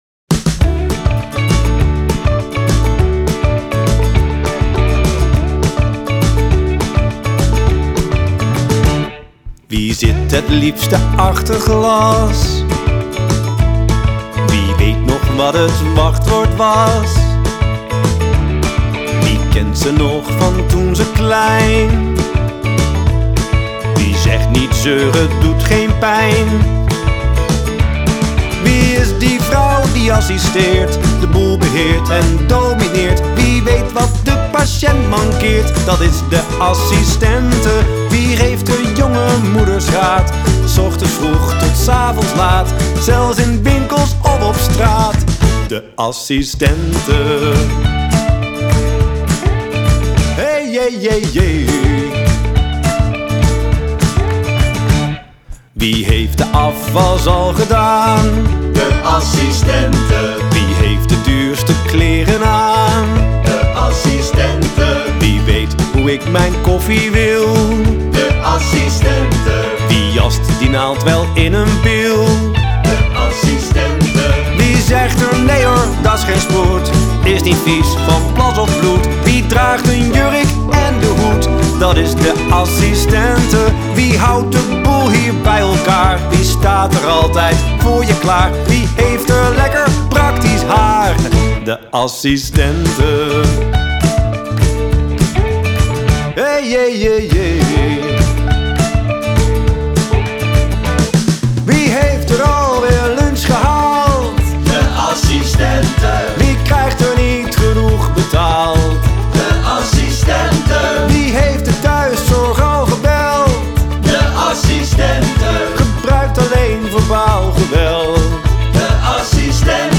Zang, gitaar
Gitaar
Bas, toetsen
Slagwerk